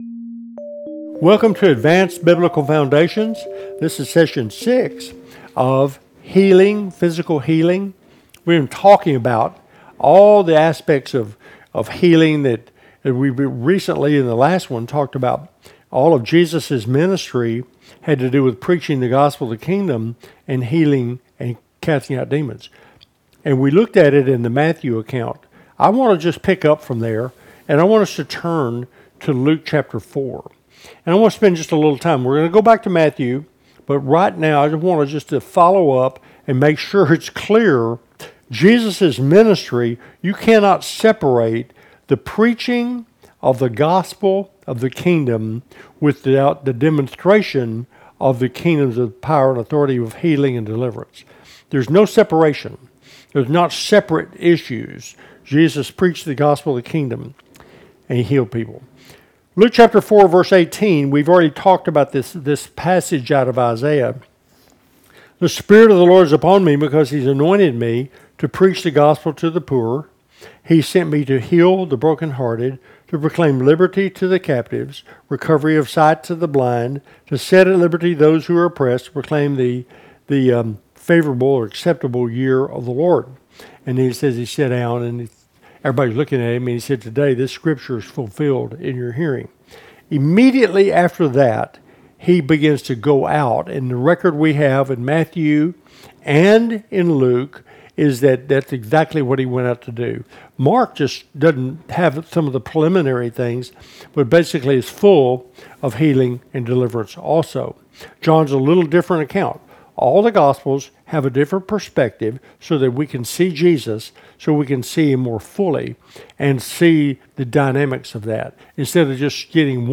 With 20-minute teachings, perfect for personal study or small group discussions, every episode concludes with a powerful prayer